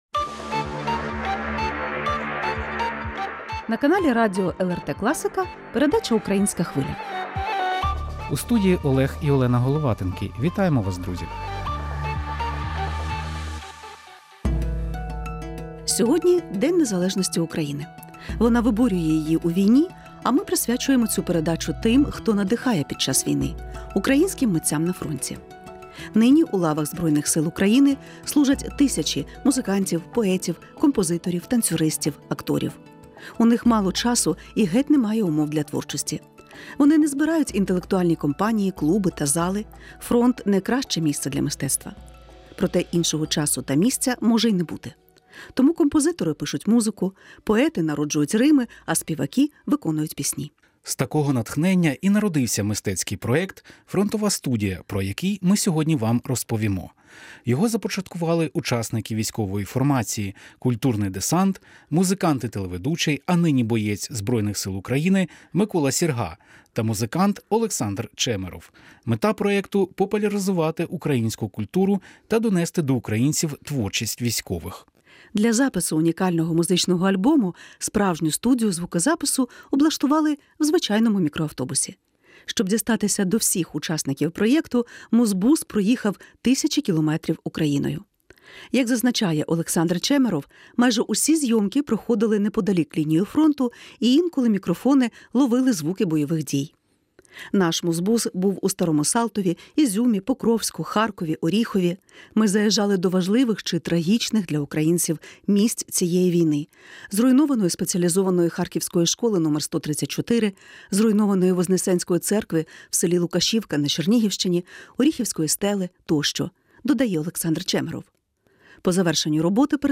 Слухаймо разом пісні у виконанні військових та волонтерів.